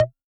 Index of /musicradar/retro-drum-machine-samples/Drums Hits/Tape Path B
RDM_TapeB_SY1-Perc04.wav